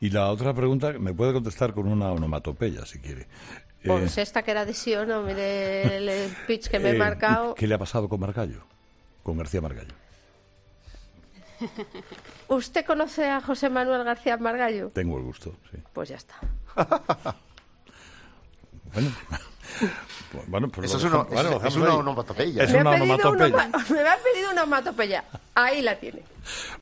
Soraya Sáenz de Santamaría ha visitado este jueves el programa 'Herrera en COPE'.
La tremenda pulla de Santamaría a Margallo durante su entrevista con Herrera